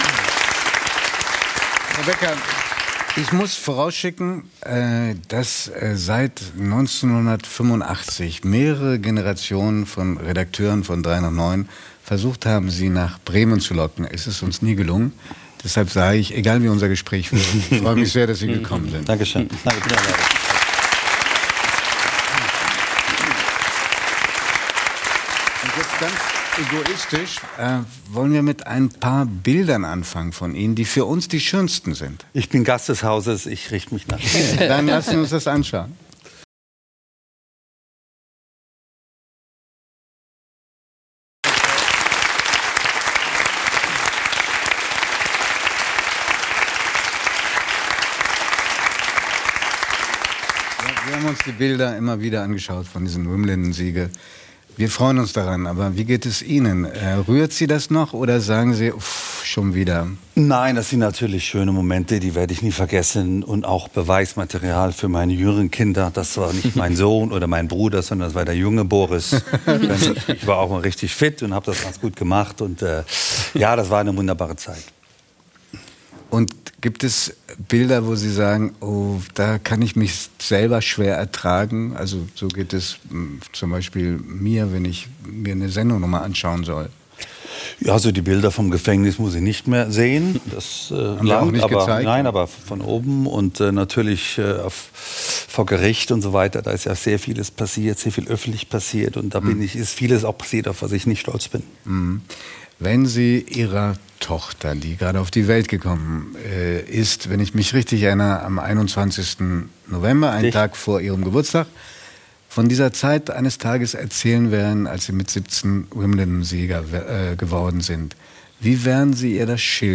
Boris Becker – Tennis-Legende ~ 3nach9 – Der Talk mit Judith Rakers und Giovanni di Lorenzo Podcast